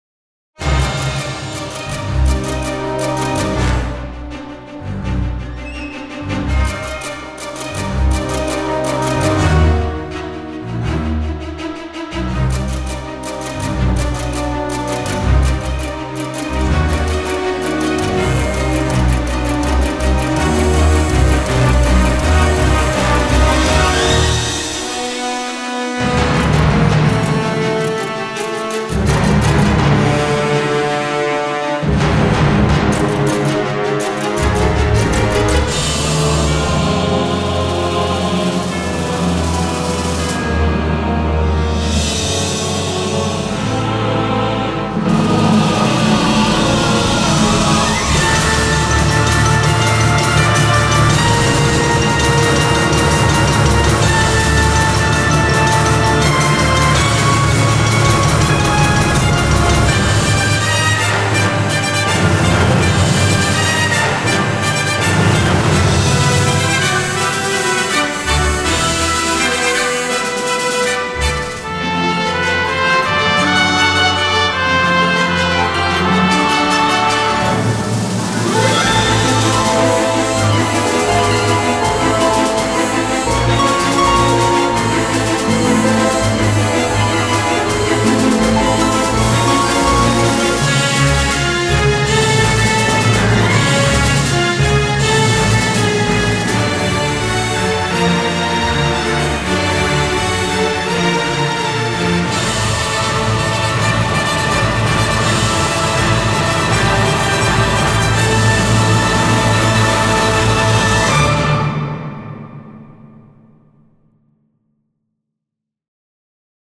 Orchestral, Chamber and Cinematic